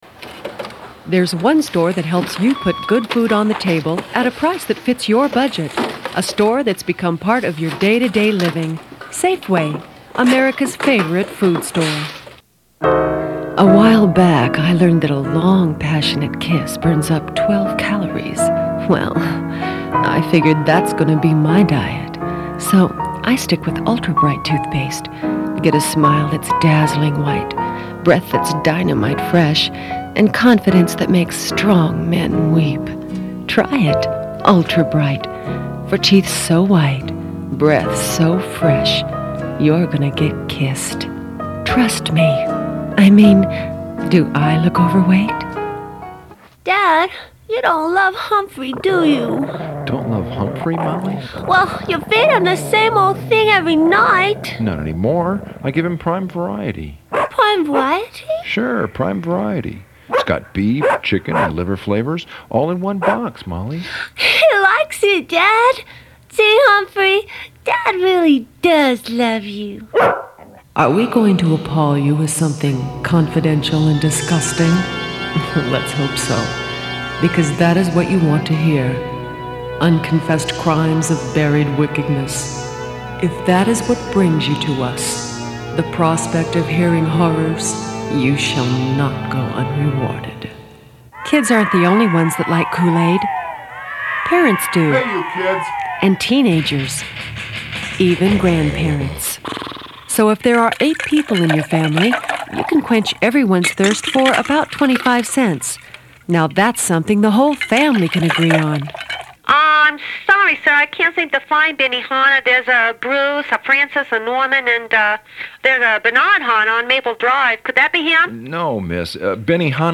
Voiceover Demo